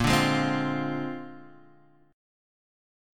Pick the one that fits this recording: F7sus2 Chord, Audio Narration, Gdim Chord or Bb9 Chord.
Bb9 Chord